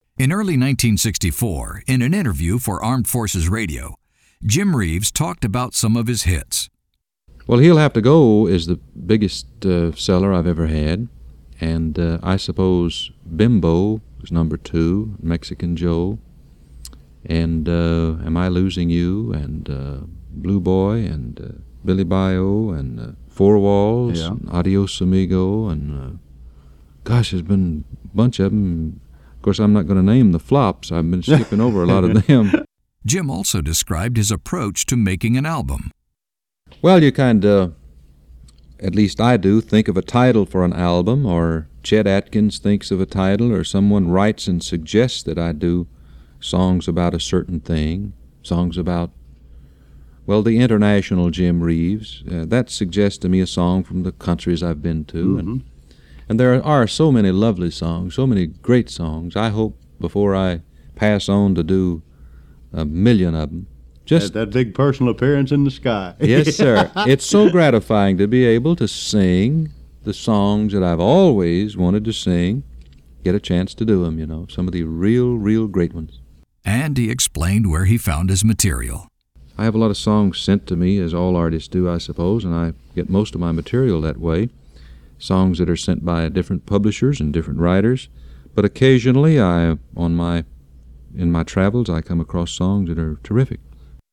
TO HEAR AN EXCEPT OF 1 OF SEVERAL UNRELEASED JIM REEVES INTERVIEWS
Jimtalking.mp3